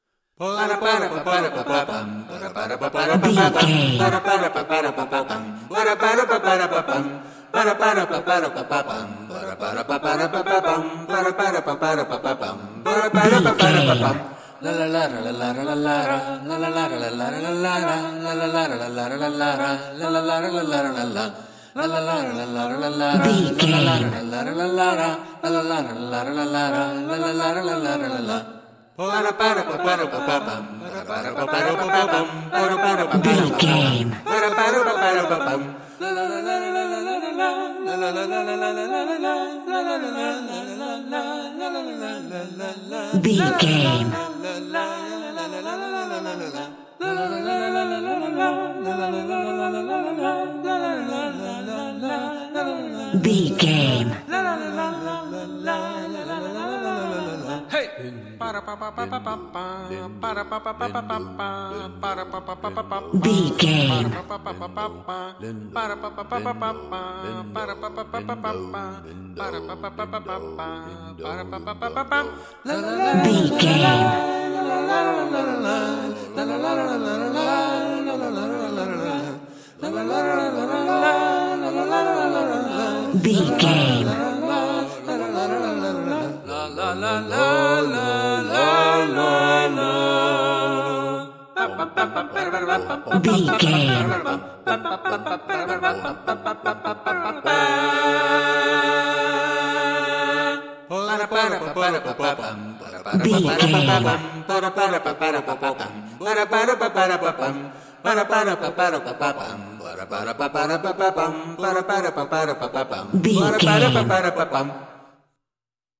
Aeolian/Minor
G♭
fun
groovy